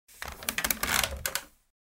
Звуки стульев
Человек садится на старый деревянный стул и тот скрипит